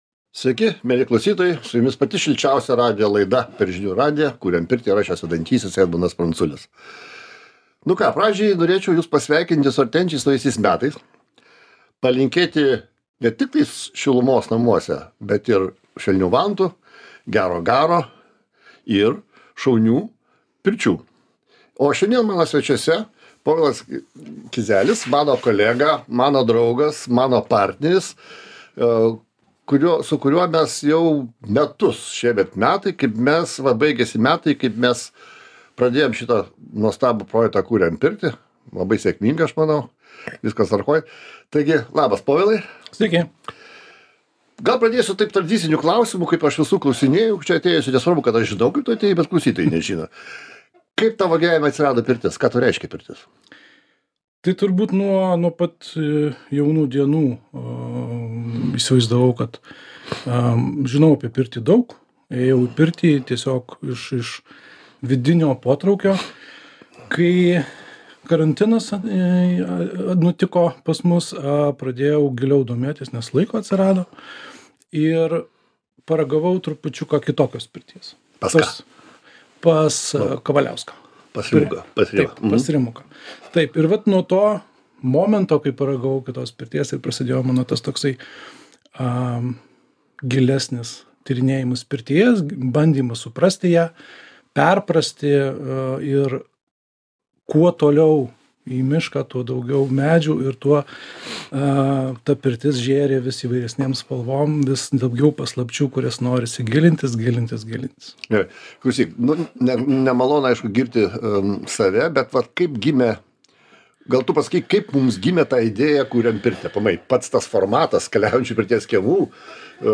Renginiai „Kuriam pirtį" – kaip jie gimė, kokie vyko 2024 metais ir kokie planai kitiems metams? Pokalbis